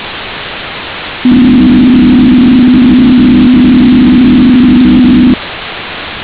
Ocean Acoustic Tompography , diffuses Empfangssignal Sonar-Ton änlich wie beim Fächerlot, hier: ohne Echo, Cluster / Geräuschteppich Sonar-Ton , einzelner Ping, hier: ohne Echo, Einzelgeräusch Wal, trompetend , Auszug aus dem Walgesang